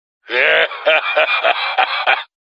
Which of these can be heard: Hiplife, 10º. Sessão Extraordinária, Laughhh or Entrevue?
Laughhh